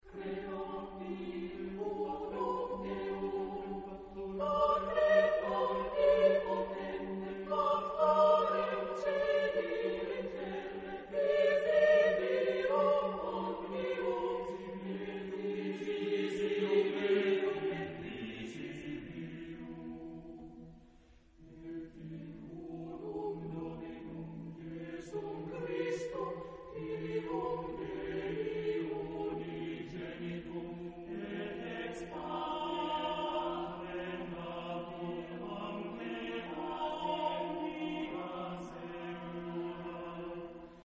SATB (4 voix mixtes).
Sacré. Extrait de messe.